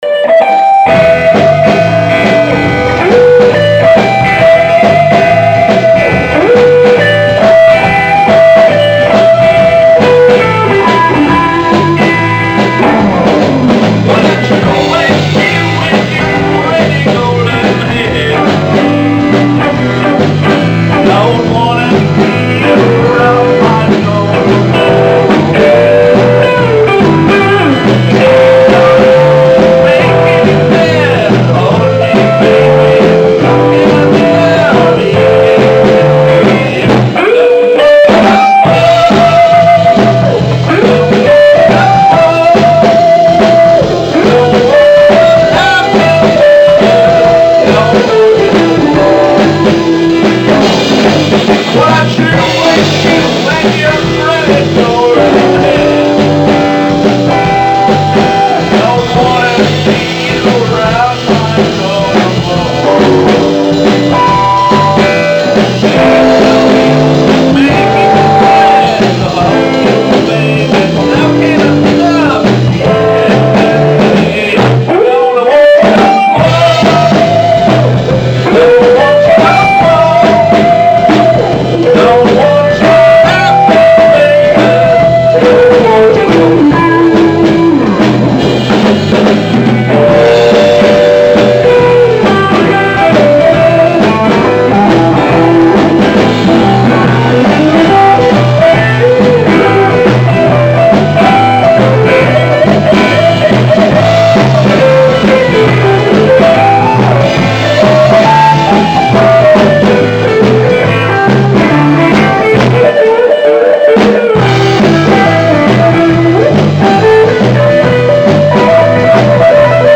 Lead Guitar
Vocals, Guitar
Keyboard
Drums
E-Bass
Im Nachhinein hat es mit diesem Auftritt dann leider doch nicht geklappt, aber immerhin hatten wir zwei intensive und erfolgreiche Proben.
Audio-Mitschnitt: